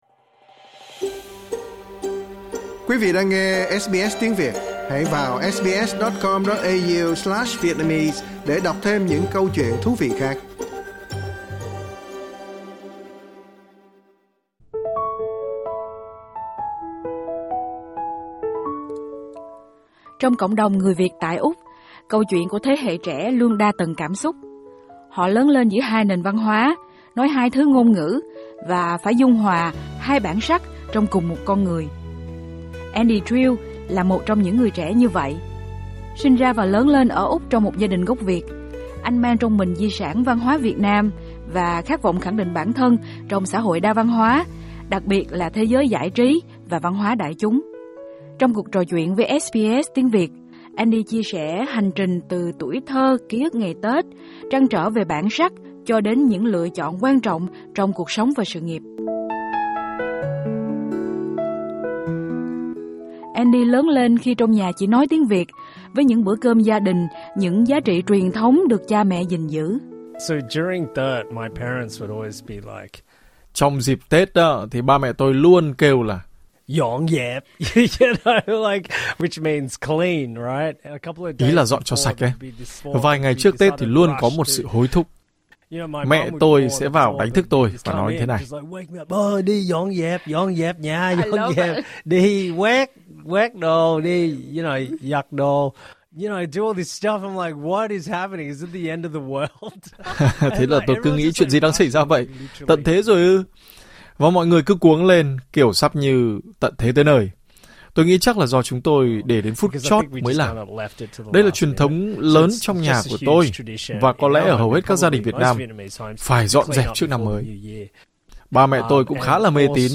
Trong cuộc trò chuyện với SBS Vietnamese